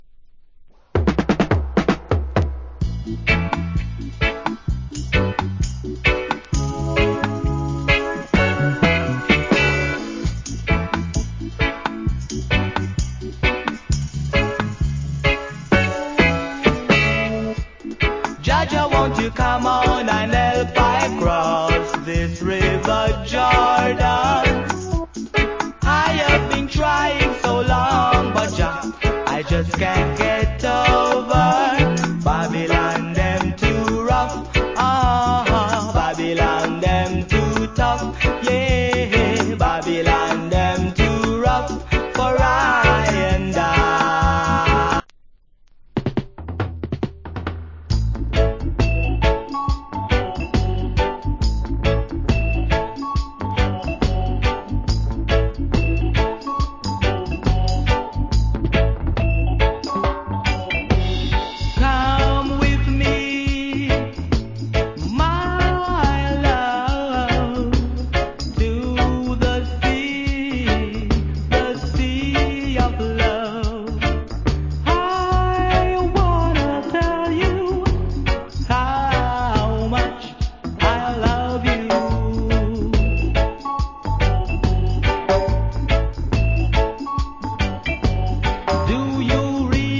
Nice Roots Rock Vocal.